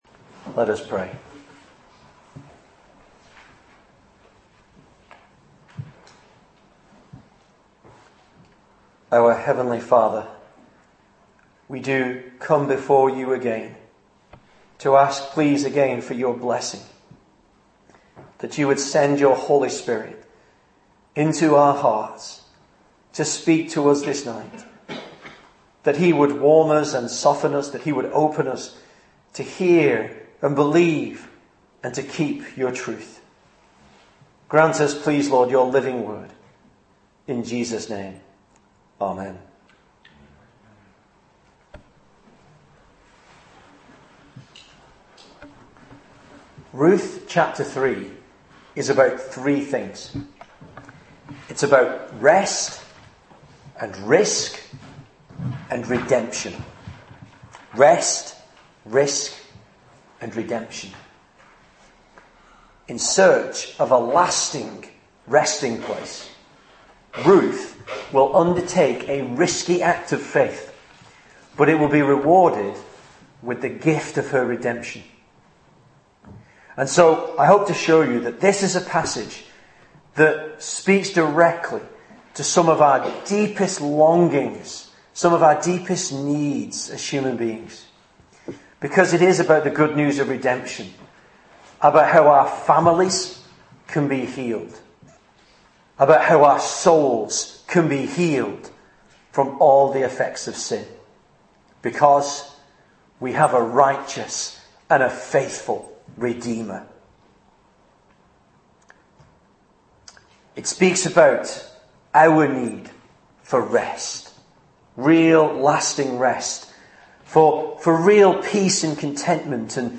God's Lovingkindness Shown in Boaz! - Bury St Edmunds Presbyterian Church